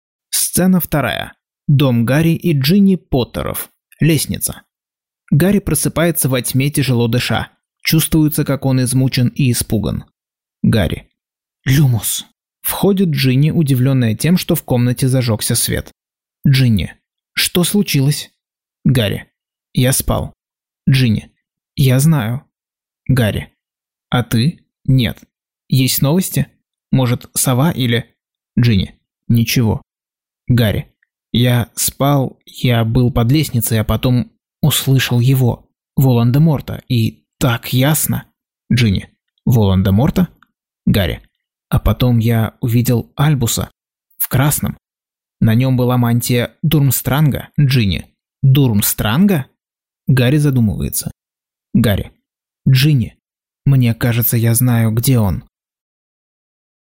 Аудиокнига Гарри Поттер и проклятое дитя. Часть 15.